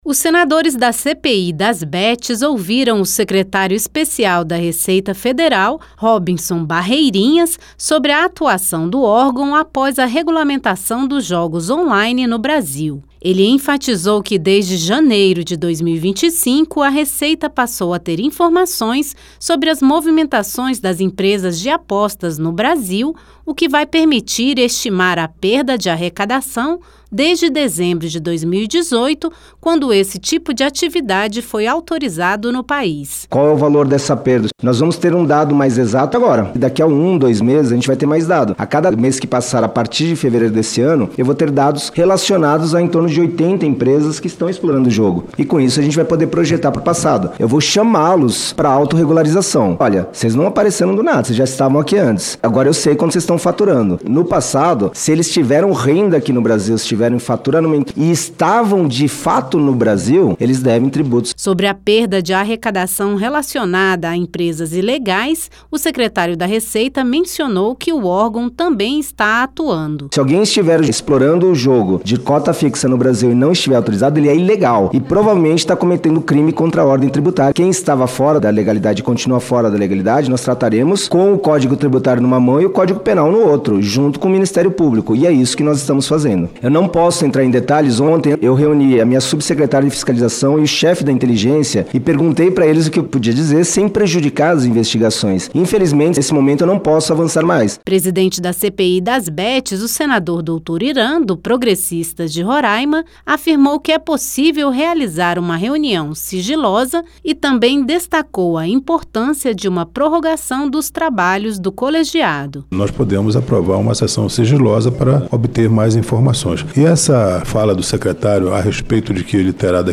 O secretário-especial da Receita Federal, Robinson Barreirinhas explicou a regulamentação das empresas de jogos on-line no Brasil e a atuação do órgão na estimativa e cobrança do que foi perdido em tributos nos últimos anos. Diante dos dados de arrecadação da Receita anunciados para os próximos meses, o presidente da CPI das Bets, senador Dr. Hiran (PP-RR), defendeu a prorrogação dos trabalhos da comissão.